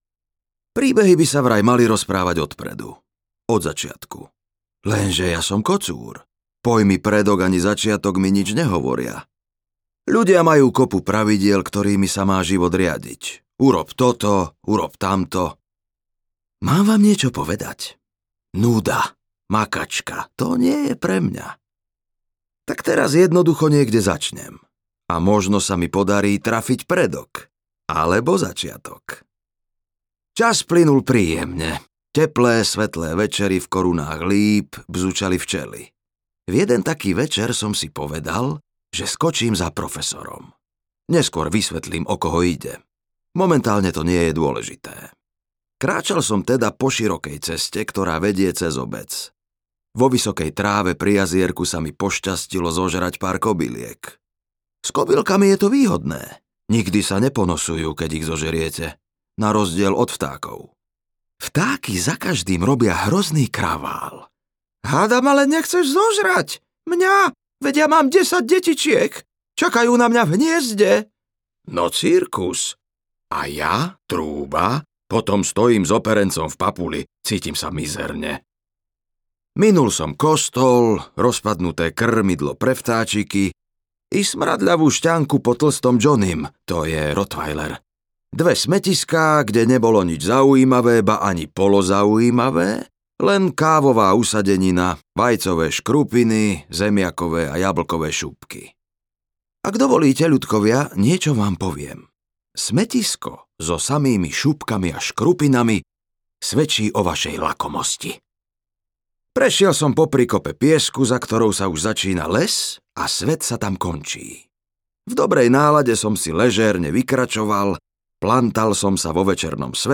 Frankie audiokniha
Ukázka z knihy